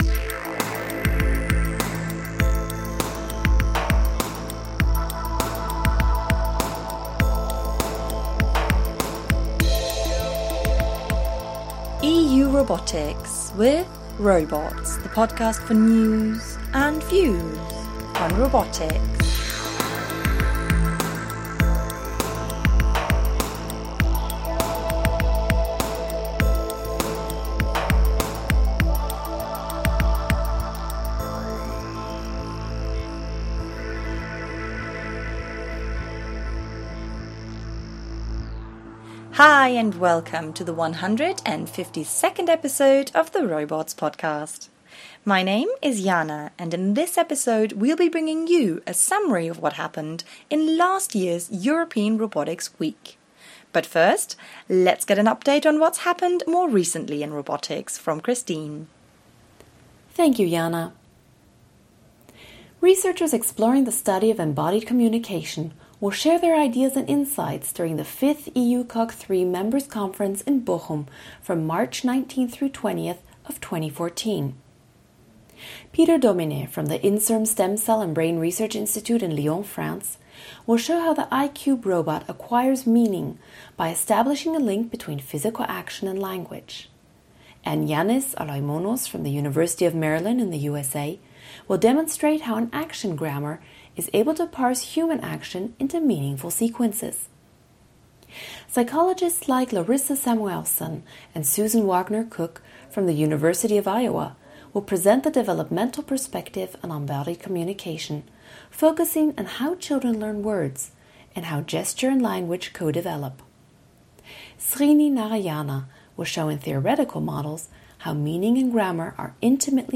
Links: Download mp3 (17MB) Subscribe to Robots using iTunes Subscribe to Robots using RSS EU Robotics Week tags: c-Events , EU Robotics Week , podcast Podcast team The ROBOTS Podcast brings you the latest news and views in robotics through its bi-weekly interviews with leaders in the field.